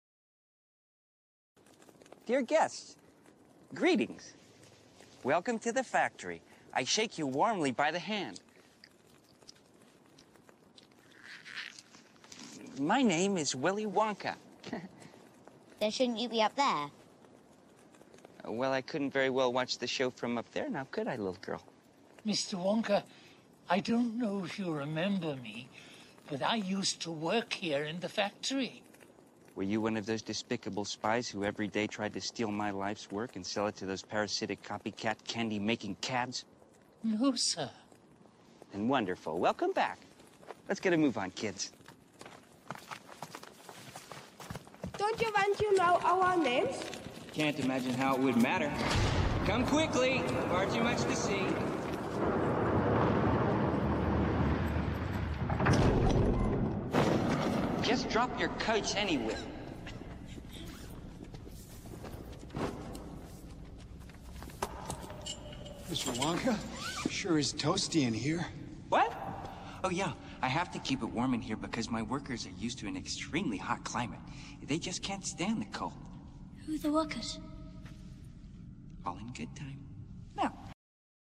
在线英语听力室影视剧中的职场美语 第118期:参观工厂的听力文件下载,《影视中的职场美语》收录了工作沟通，办公室生活，商务贸易等方面的情景对话。